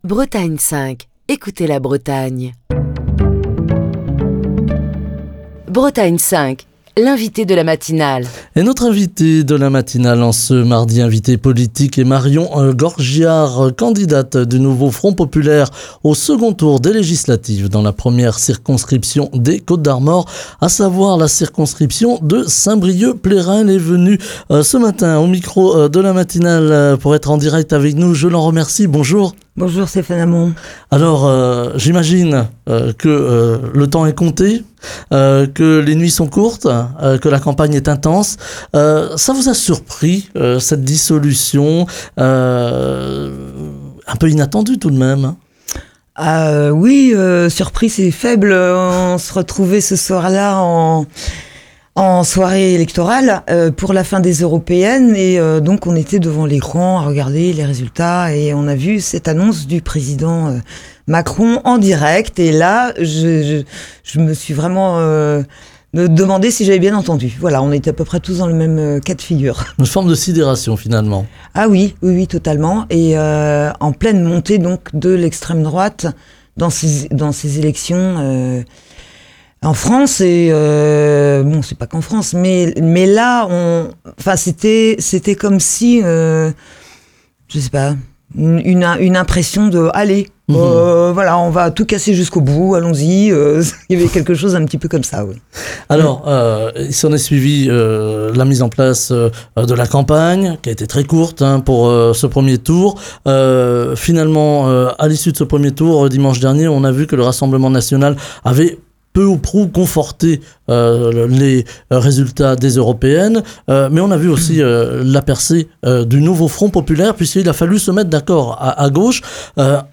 Écouter Télécharger Partager le podcast Facebook Twitter Linkedin Mail L'invité de Bretagne 5 Matin